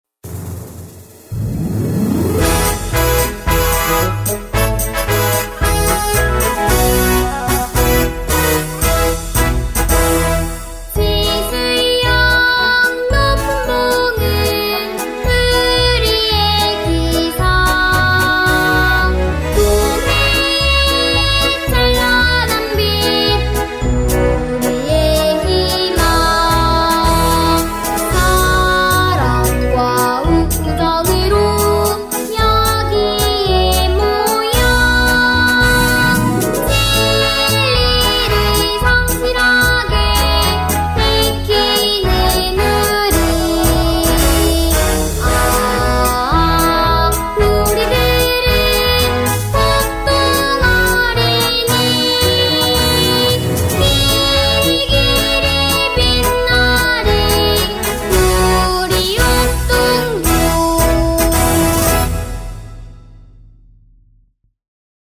옥동초등학교 교가 음원 :미추홀시민아카이브